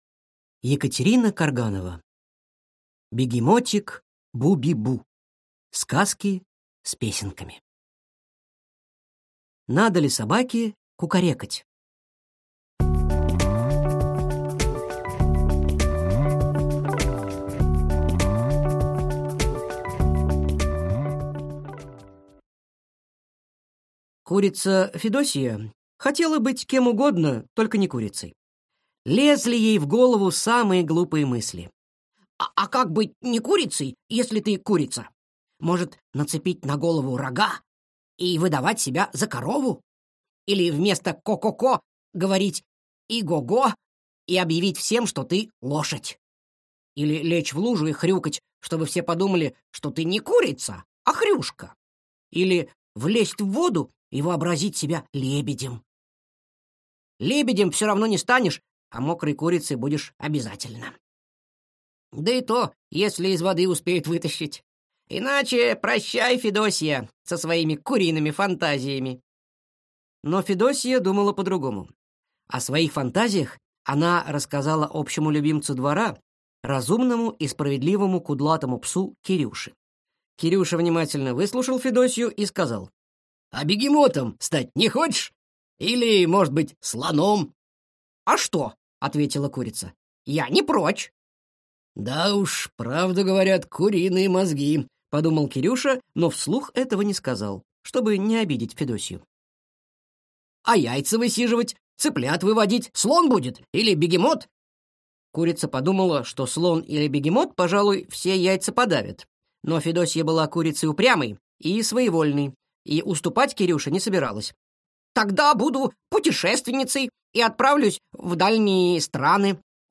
Аудиокнига Бегемотик Буби-бу. Сказки с песенками | Библиотека аудиокниг